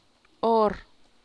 onse[ó’nseh]